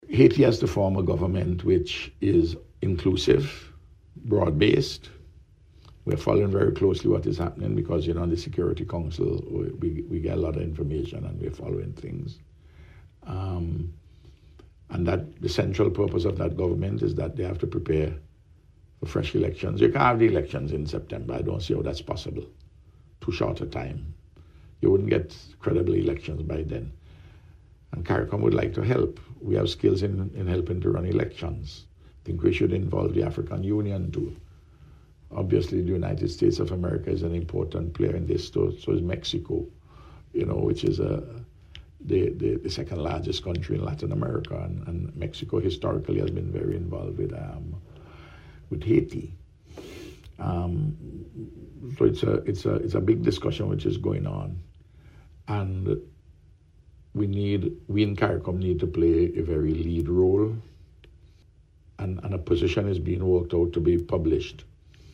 The Prime Minister addressed the issue while speaking on this week’s edition of Round Table Talk.